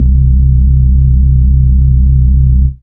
Menace Throb Bass.wav